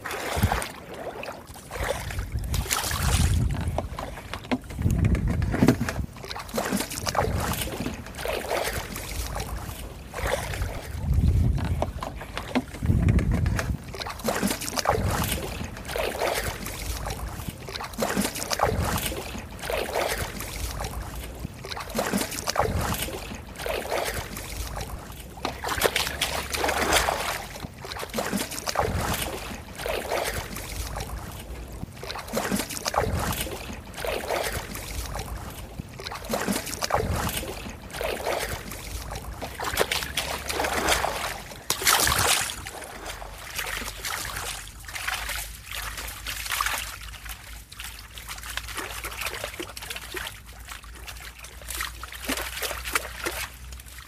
Tiếng Lội Nước… mò cua, bắt cá….
Thể loại: Tiếng động
Description: Tiếng lội nước, mò cua bắt cá, bắt ốc vang lên rì rào, bì bõm, lạch xạch và vỗ rào rạt, xáo động lội nước, rào rào, lụp xụp dưới nước, gợi cảm giác sinh động... mô phỏng bọt nước, sột soạt của nước, tiếng chân đi trong nước lũ, lũ lụt sột soạt...
tieng-loi-nuoc-mo-cua-bat-ca-www_tiengdong-com.mp3